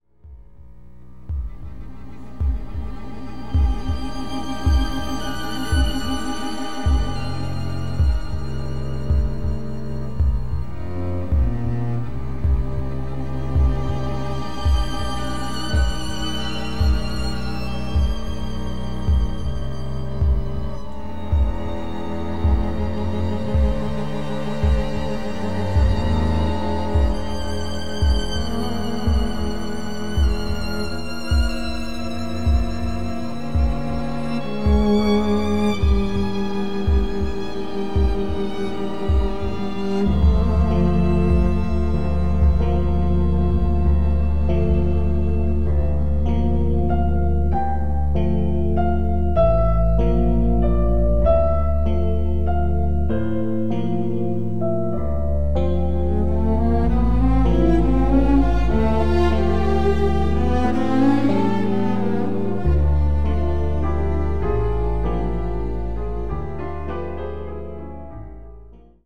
strings
piano